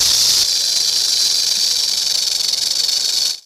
sounds_snake_rattle.ogg